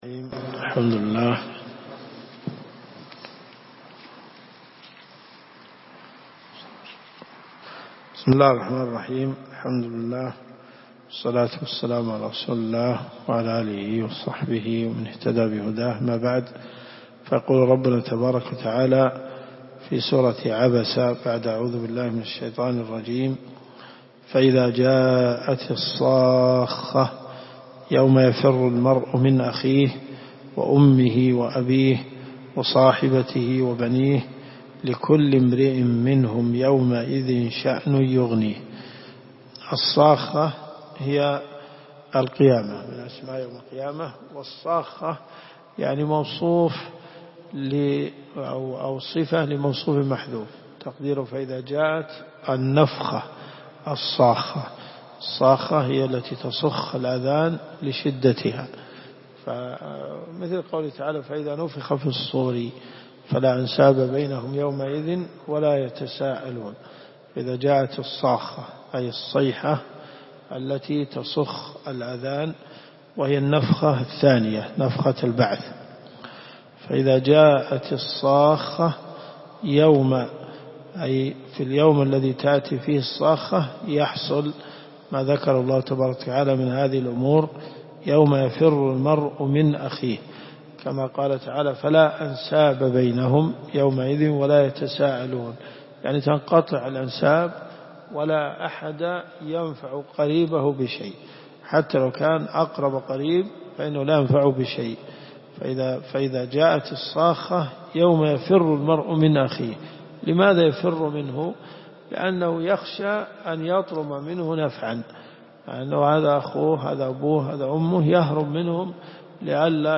تفسير القران الكريم
دروس صوتيه ومرئية تقام في جامع الحمدان بالرياض